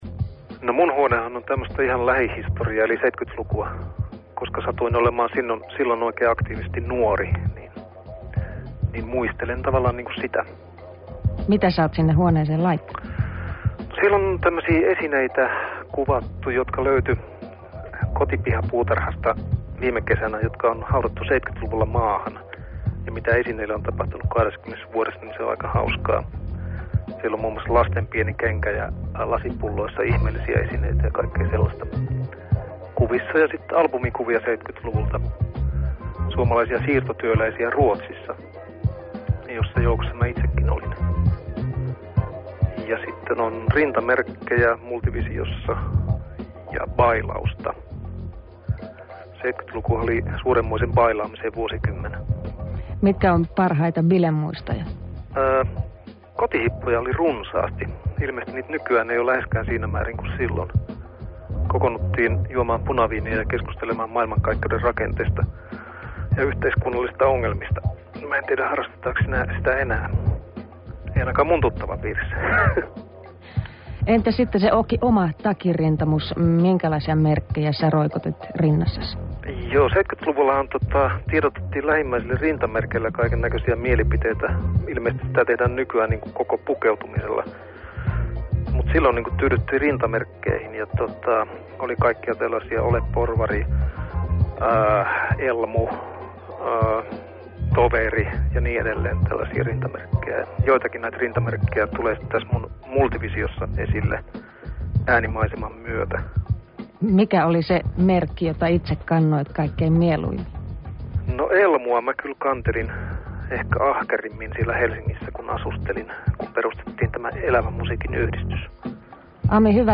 Radiohaastattelu hommasta.